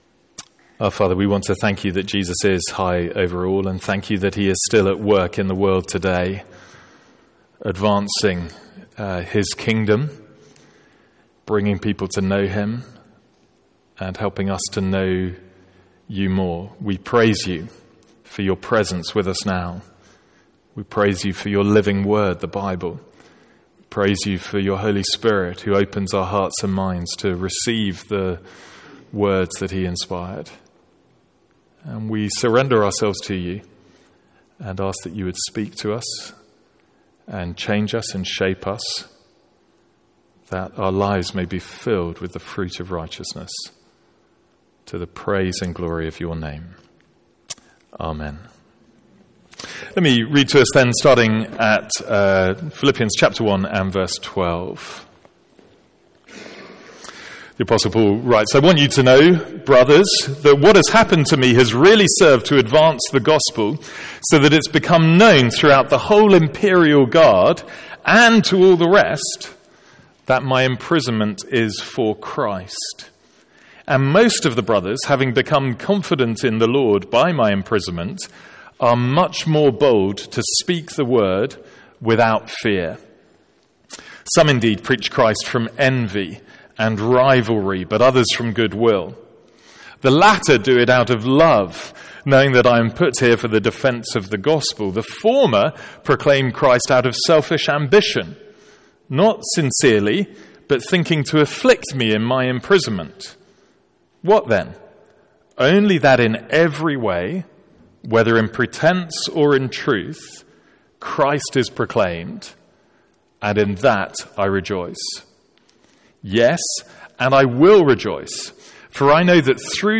From our morning series in Philippians.